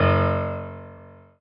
piano-ff-10.wav